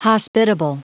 Prononciation du mot hospitable en anglais (fichier audio)
hospitable.wav